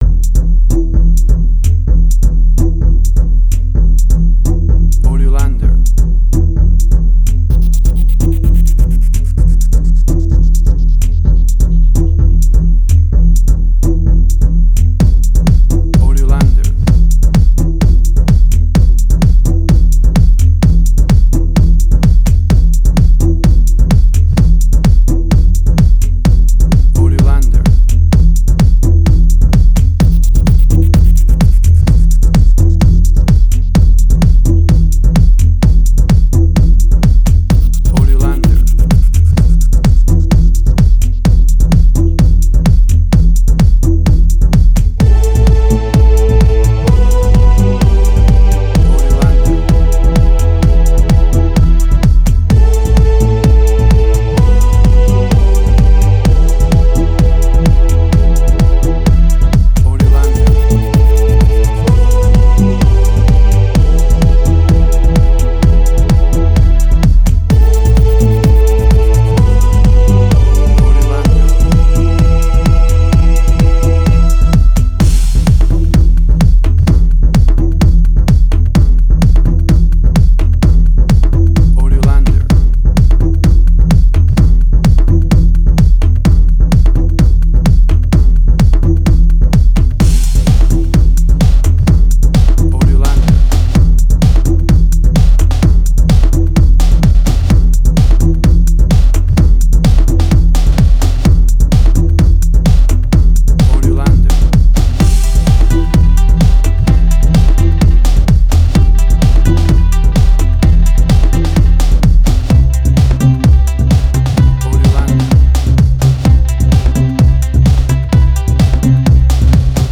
Jewish Techno Trance.
Tempo (BPM): 128